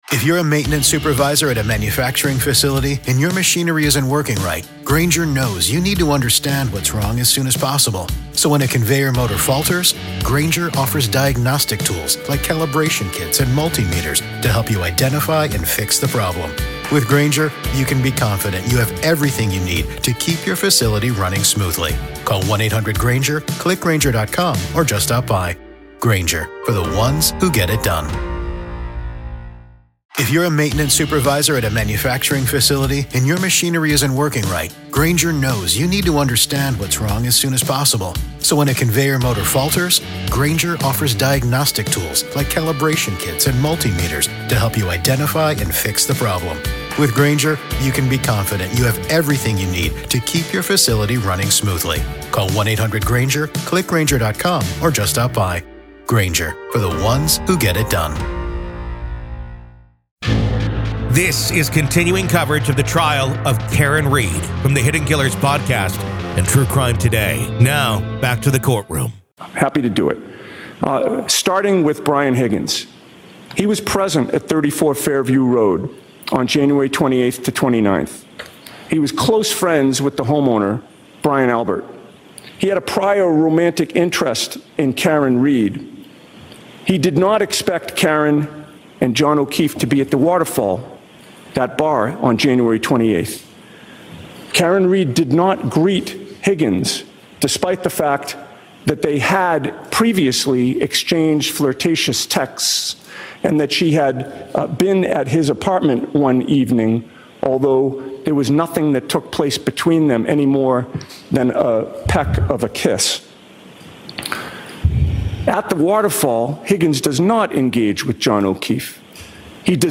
Welcome to a special episode of "The Trial of Karen Read," where today, we find ourselves inside the courtroom for a critical pre-trial hearing in the case against Karen Read.